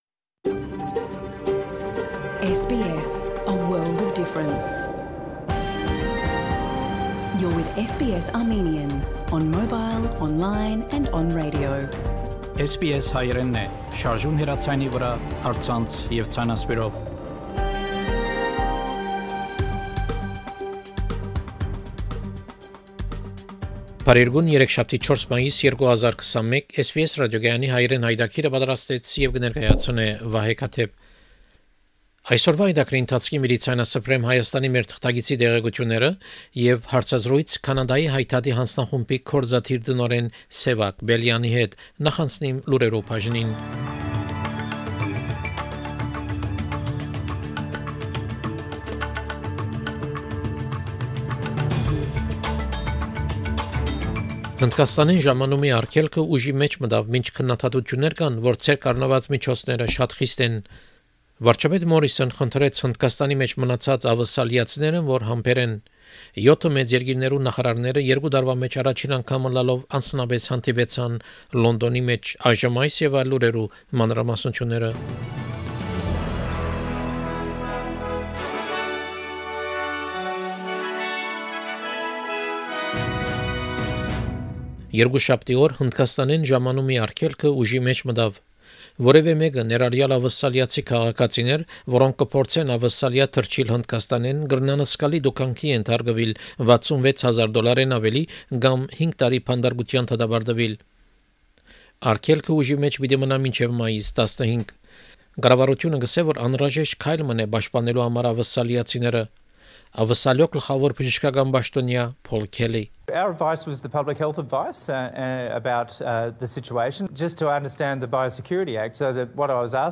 SBS Armenian news bulletin – 4 May 2021
SBS Armenian news bulletin from 5 May 2021 program.